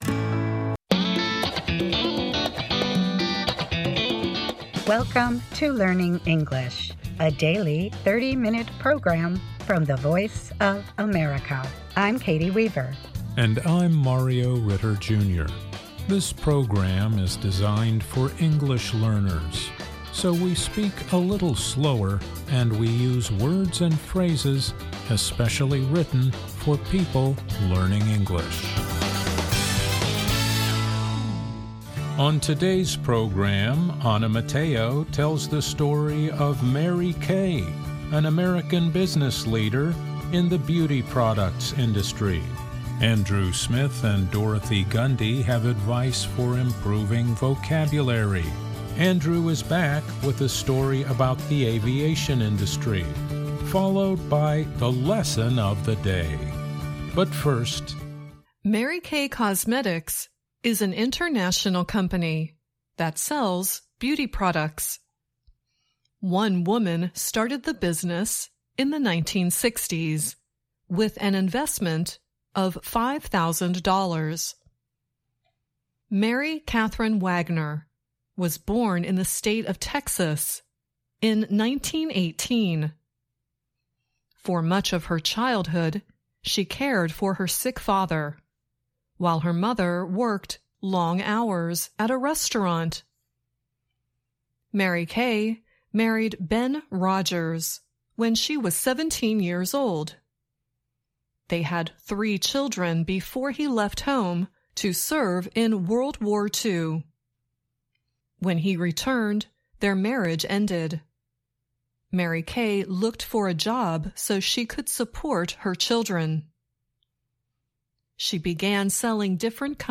Learning English programs use a limited vocabulary and short sentences. They are read at a slower pace than VOA's other English broadcasts.